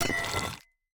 minecraft / sounds / item / axe / wax_off2.ogg
wax_off2.ogg